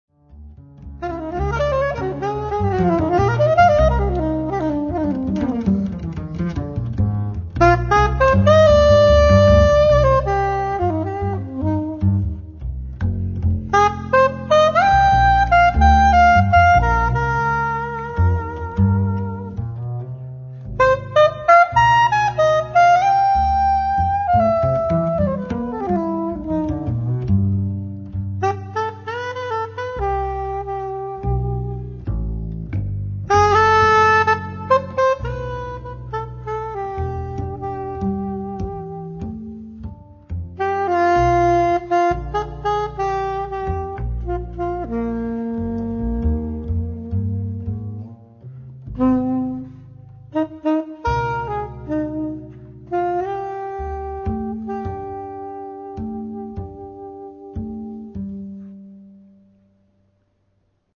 soprano and tenor sax
double bass
in duo da contrabbasso e soprano